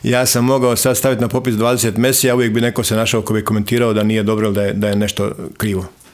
Izbornik je u intervjuu za Media servisu ponovio: